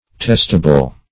Testable \Test"a*ble\, a. [See Testament.]